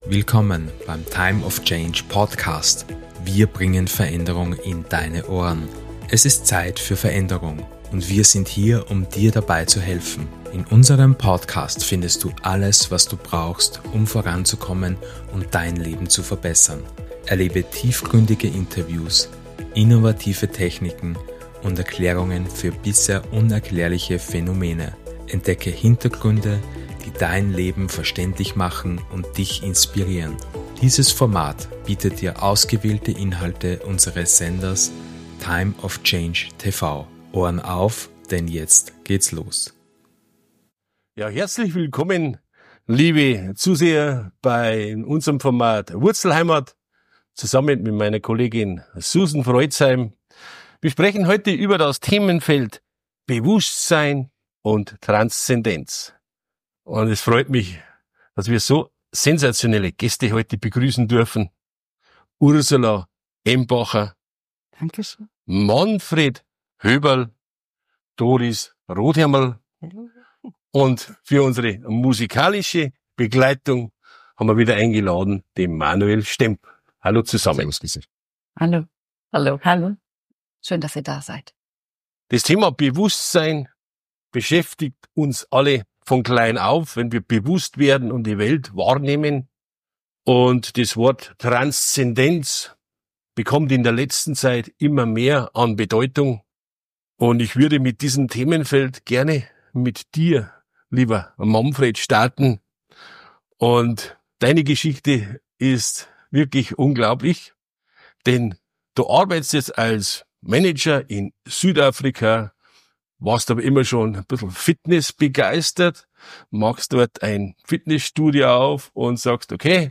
Entdecke in dieser faszinierenden Folge von Wurzlheimat die tiefgründigen Gespräche über Bewusstsein und Transzendenz.